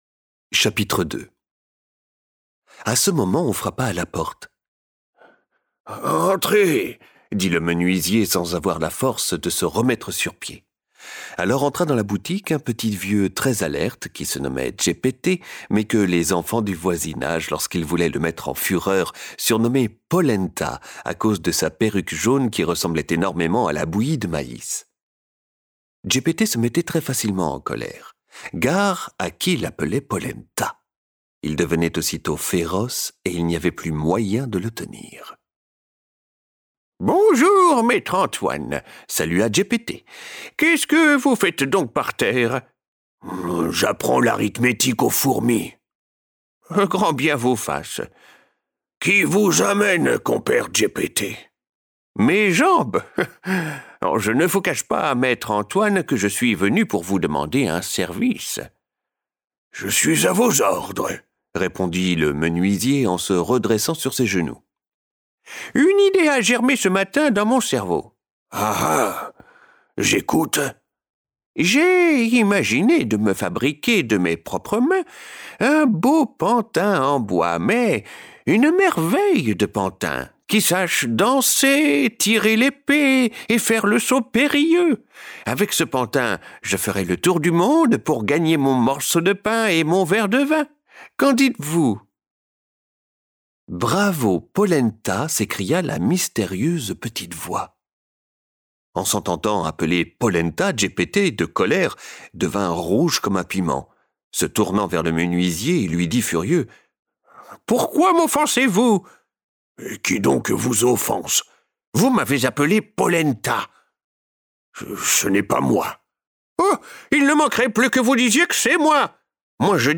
Envie de découvrir de la littérature audio en famille ?